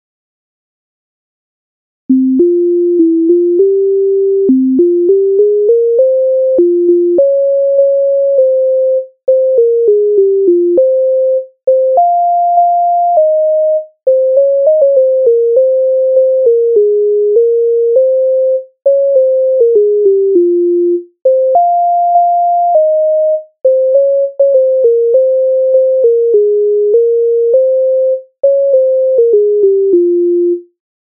Українська народна пісня